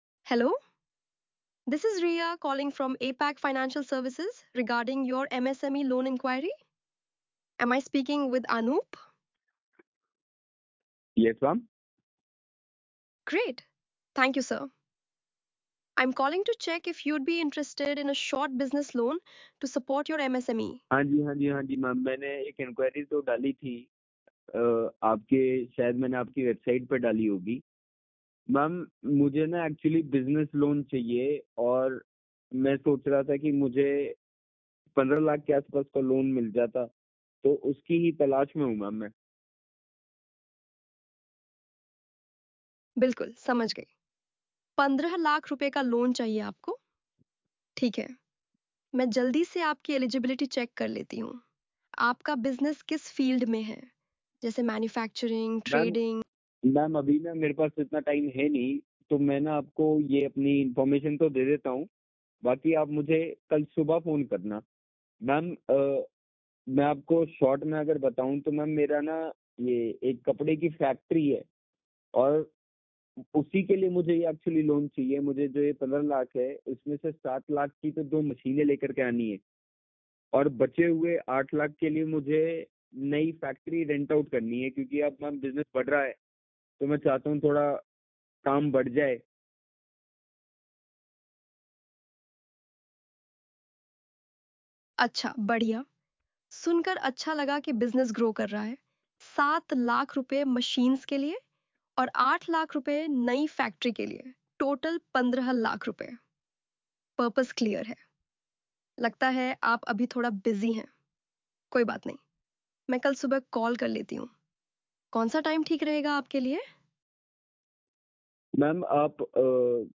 • English Indian
• Female